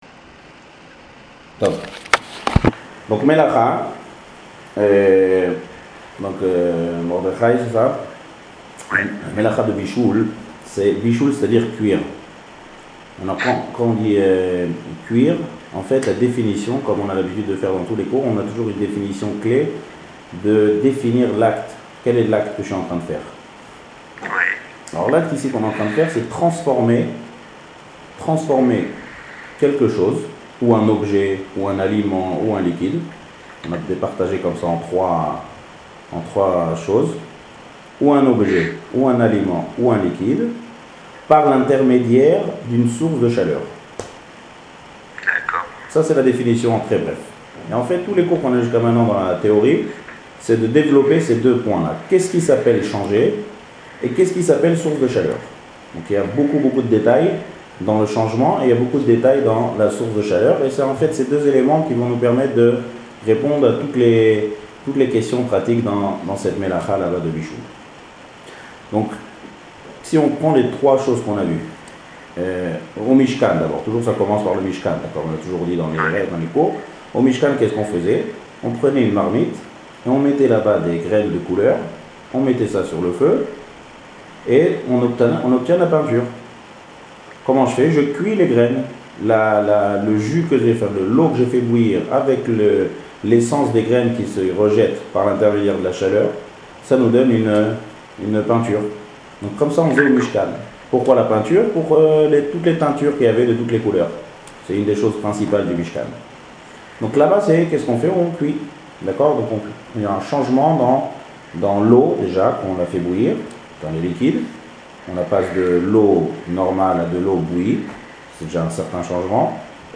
Le cours a lieu dans les locaux de l’institution « Od Avinou Hay » que nous remercions.
Le cours est assuré via le système Webex qui assure une meilleure qualité de son.